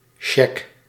Ääntäminen
Ääntäminen Tuntematon aksentti: IPA: /t͡ɕek/ Haettu sana löytyi näillä lähdekielillä: venäjä Käännös Ääninäyte 1. cheque {m} Translitterointi: tšek.